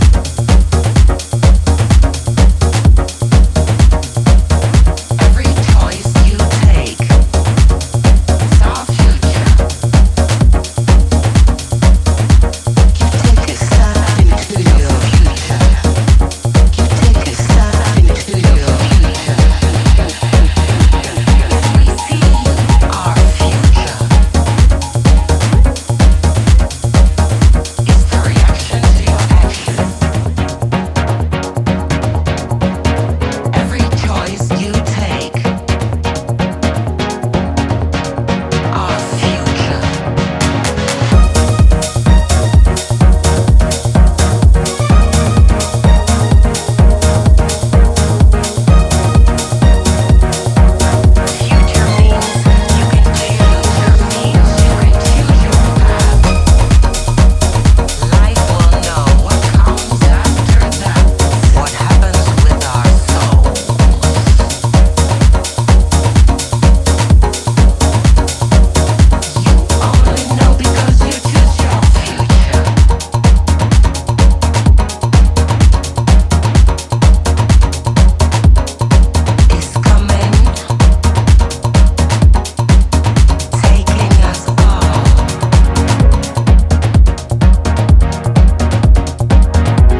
ダビーなコードとストリングスのスタブ